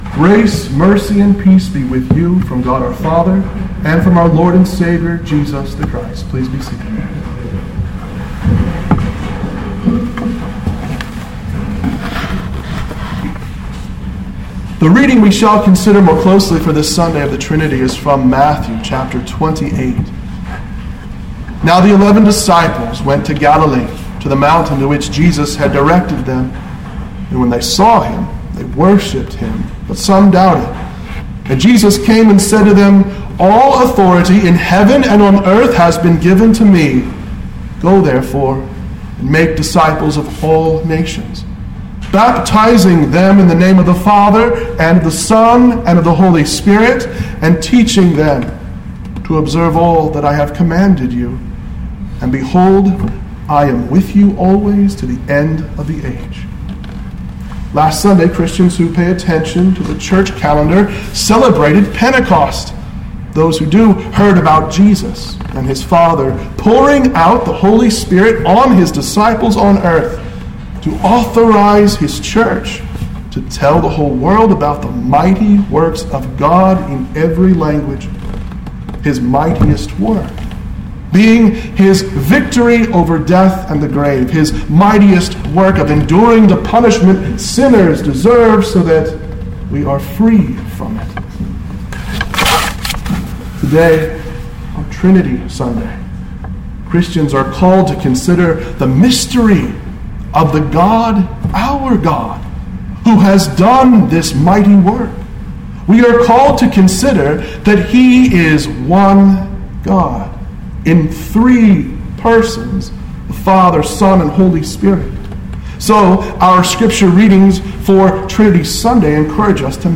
sermon-for-the-holy-trinity.mp3